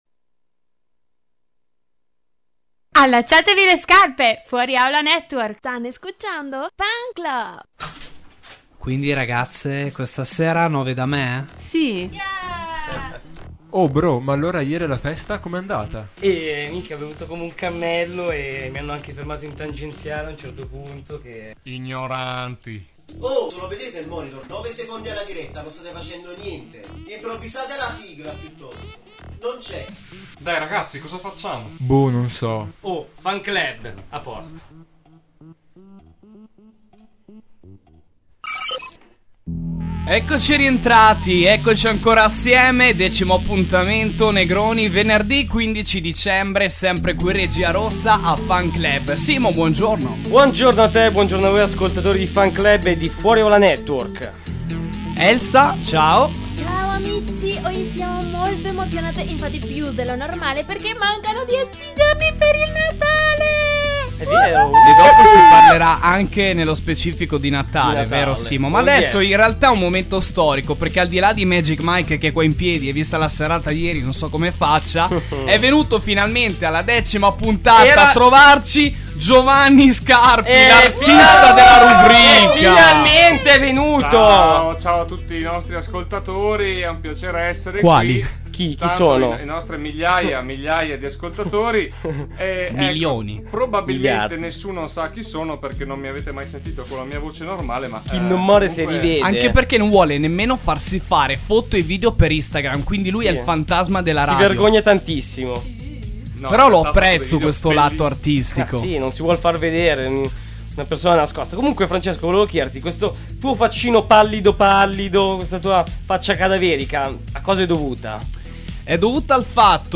Direttamente dalla regia rossa degli studi di Fuori Aula Network, torna FANClub.